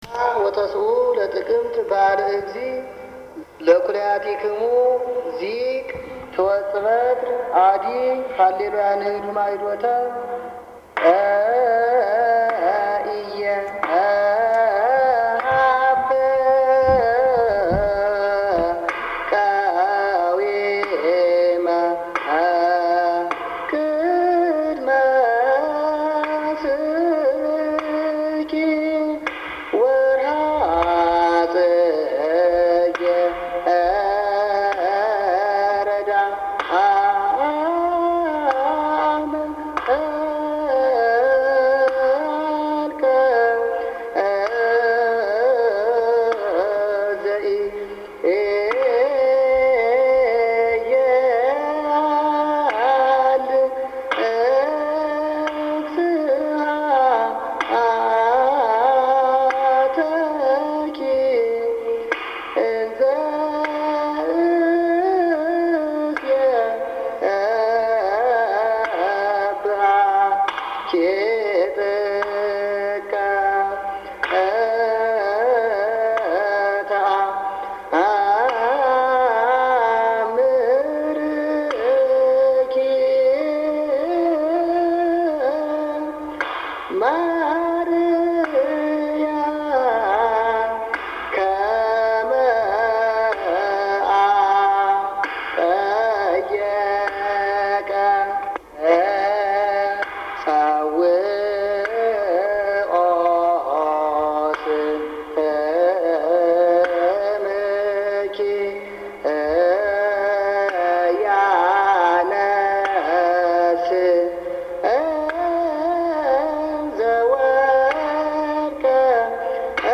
1aquaquam zema non stop.MP3